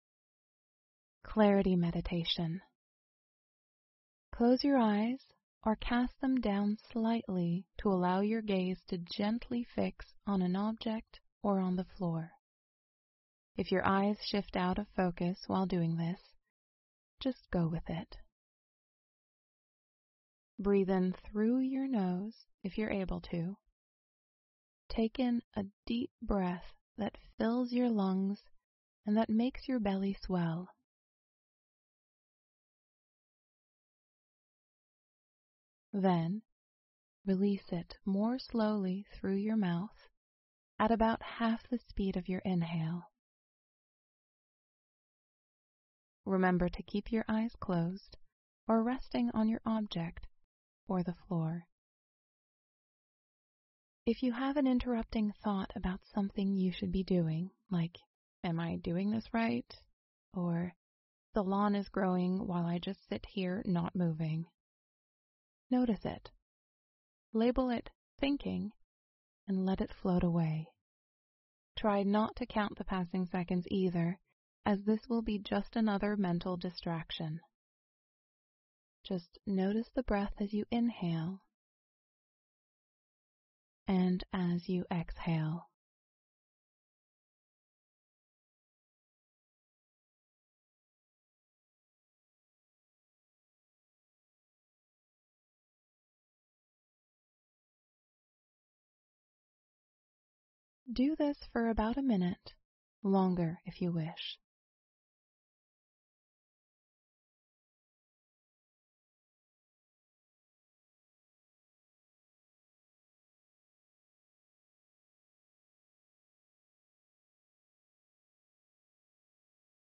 Clarity Meditation